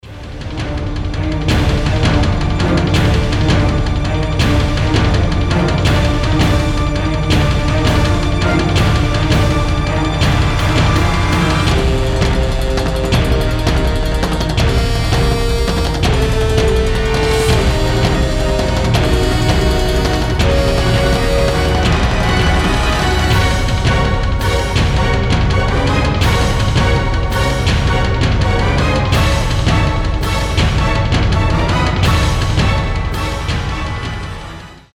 без слов , эпичные